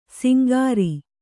♪ singāri